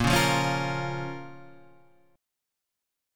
A# Minor